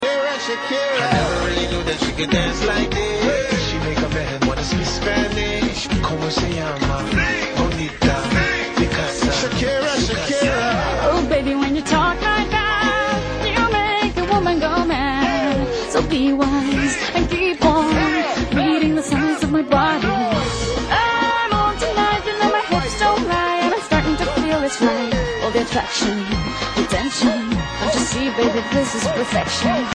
Зажигательный хит